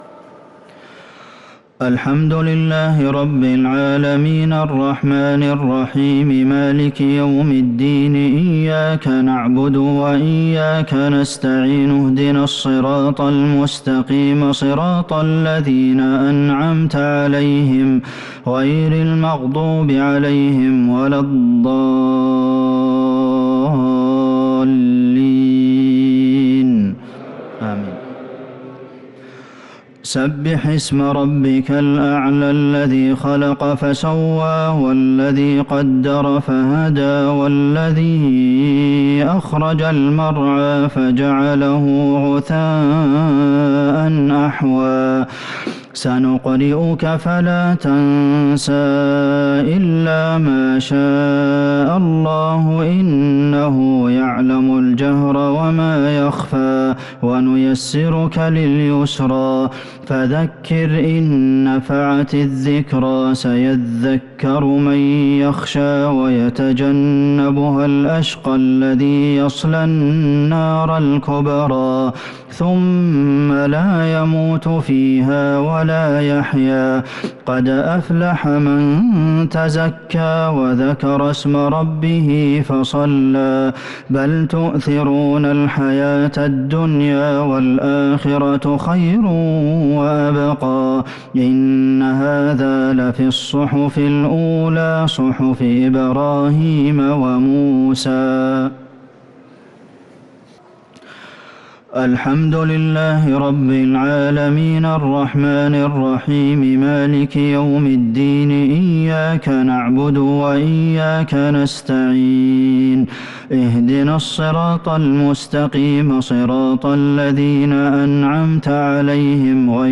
الشفع و الوتر ليلة 8 رمضان 1443هـ | Witr 8st night Ramadan 1443H > تراويح الحرم النبوي عام 1443 🕌 > التراويح - تلاوات الحرمين